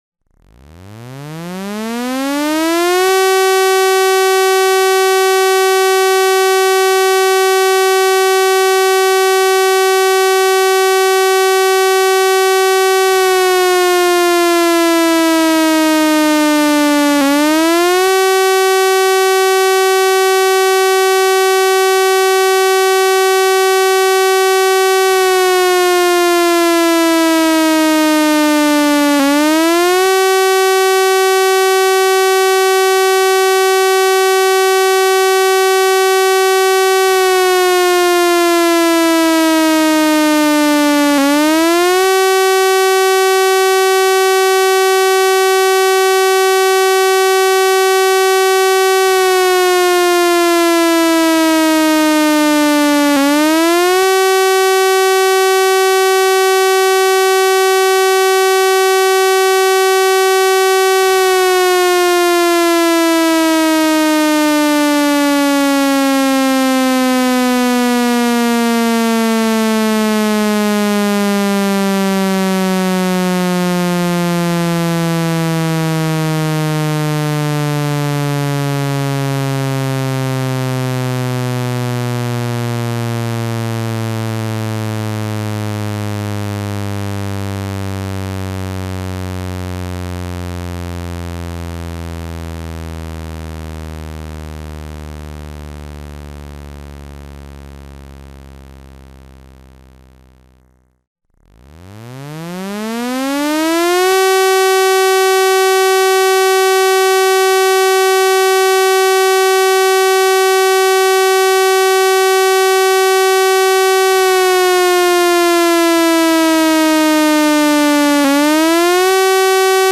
alarme-incendie-le-son-de-lalarme-incendie-afnor